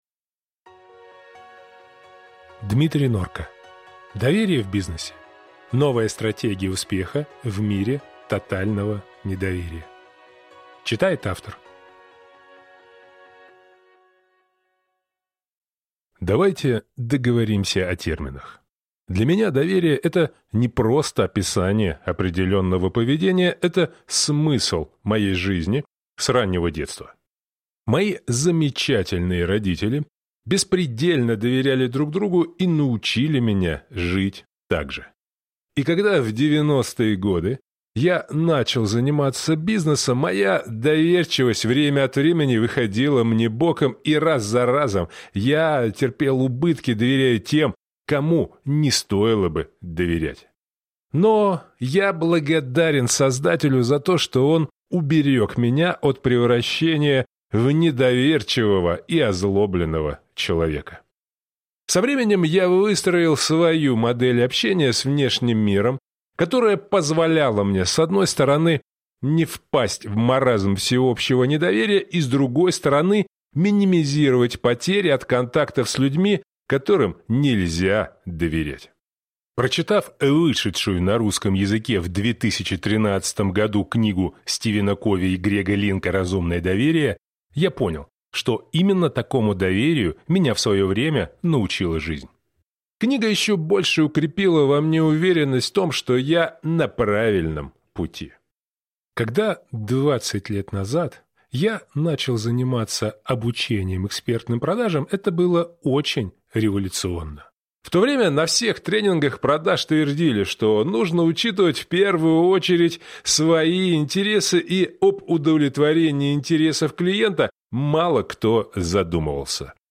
Аудиокнига Доверие в бизнесе: Новая стратегия успеха в эпоху тотального недоверия | Библиотека аудиокниг